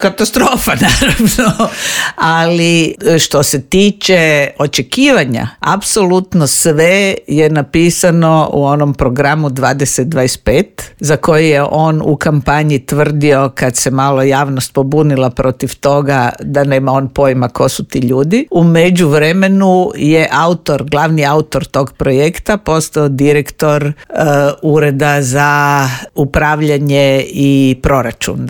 U Intervjuu Media servisa ugostili smo bivšu ministricu vanjskih i europskih poslova Vesnu Pusić koja kaže da je civilno društvo u Srbiji pokazalo da tamo postoji demokratska javnost što se poklopilo sa zamorom materijala vlasti: